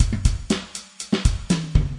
标签： 回路 节奏 命中 岩石 冲击 120-BPM 打击乐器 节拍 敲击循环 量化 鼓环 常规
声道立体声